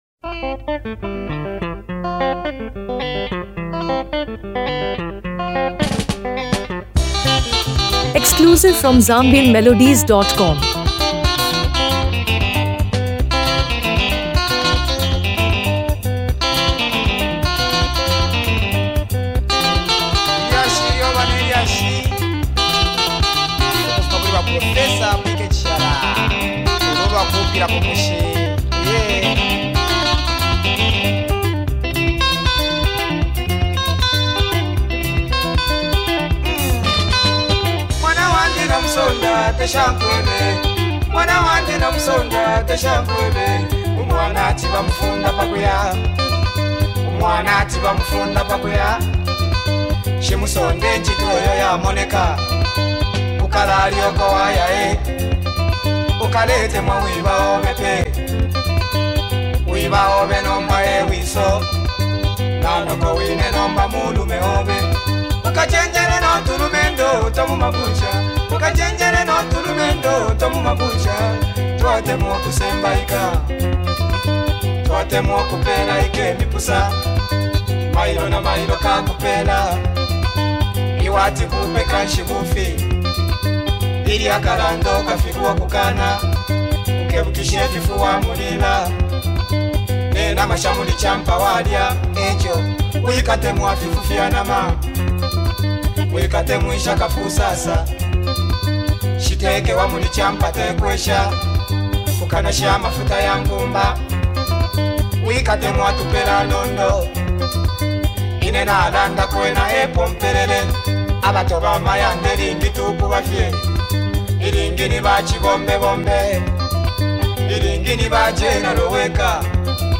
blends traditional Zambian sounds with contemporary elements